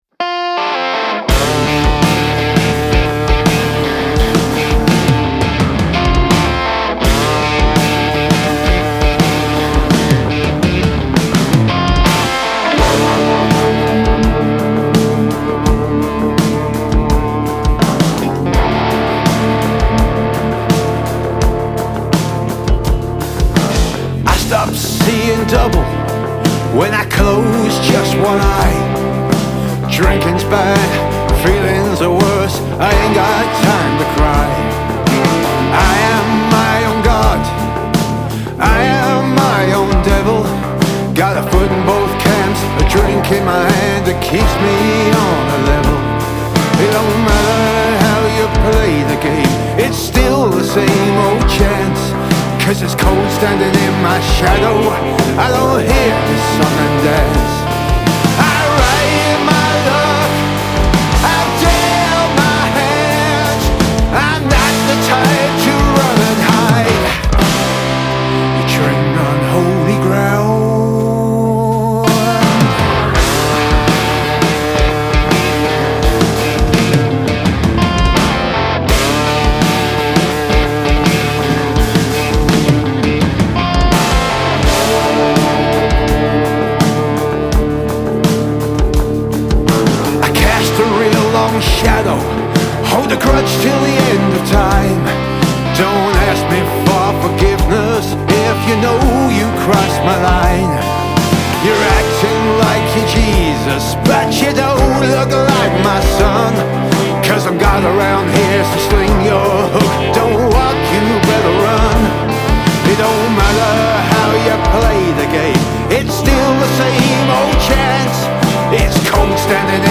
Male Vocal, Guitar, Pedal Steel, Bass Guitar, Drums